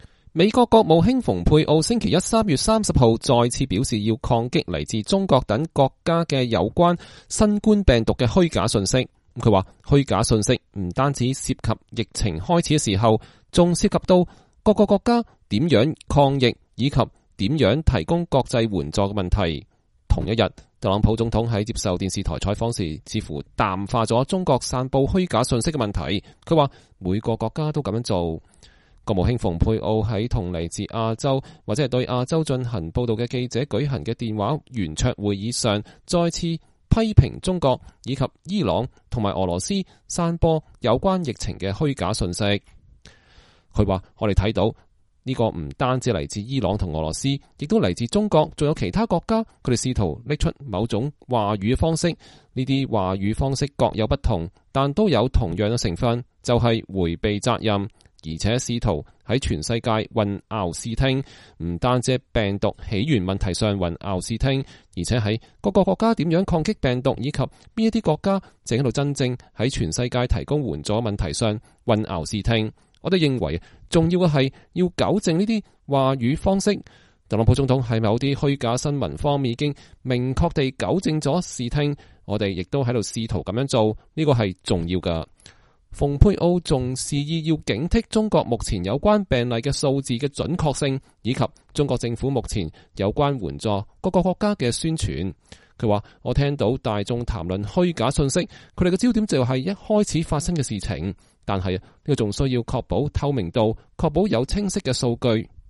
美國國務卿蓬佩奧在國務院舉行記者會。(2020年3月25日)